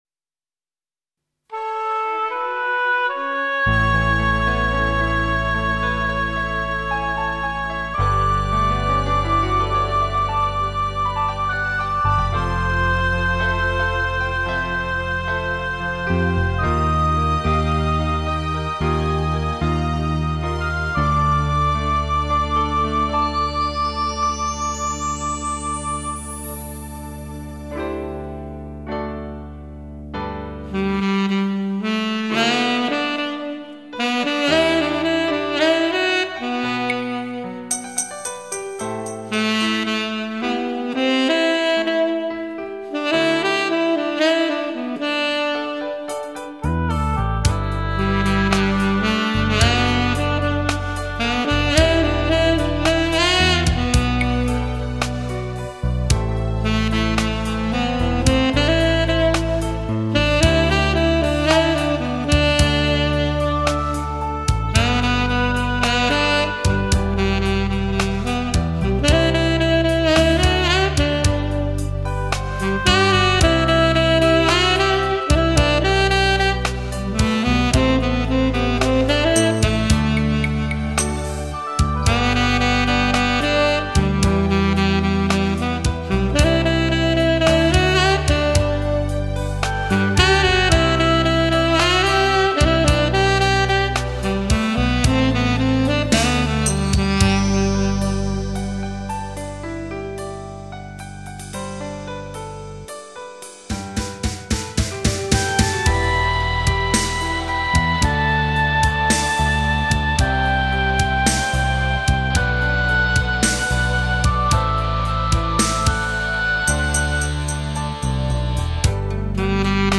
青春的气派 浪漫的情调 劲爆金榜流行曲